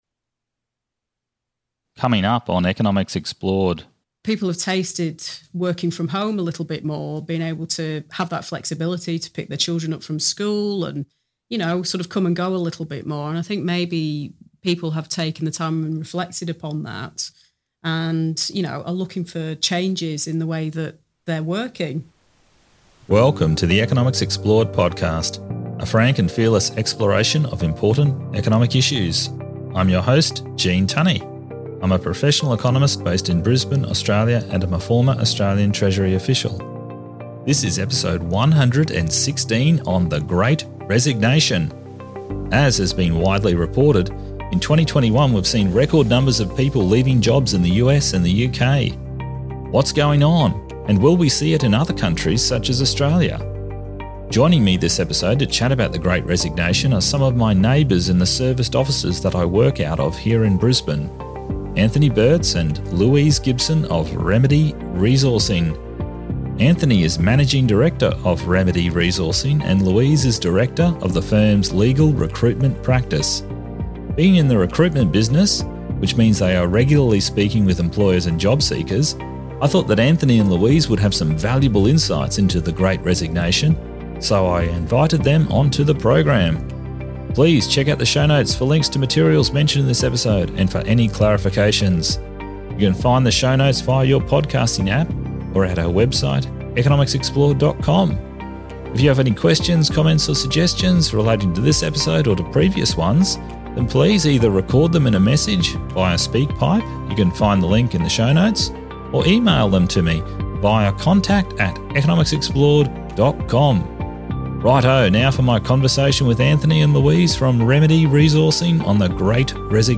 This episode features a conversation